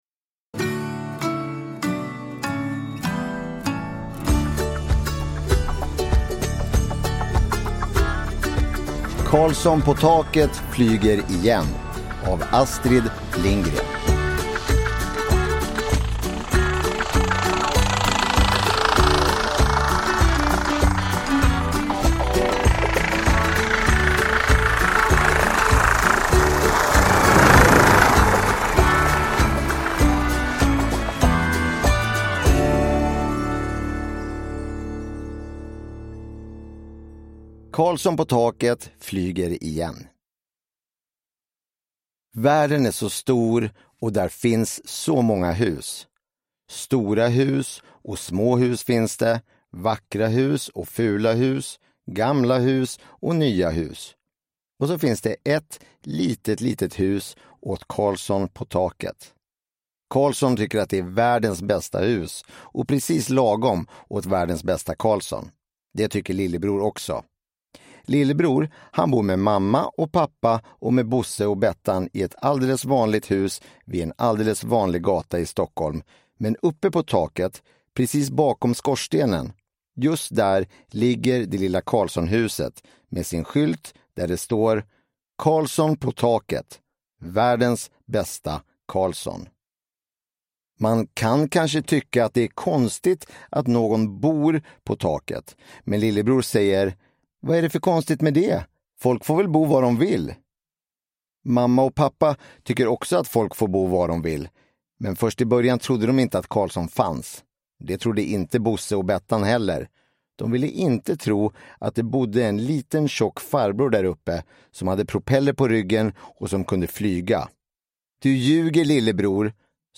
Karlsson på taket flyger igen – Ljudbok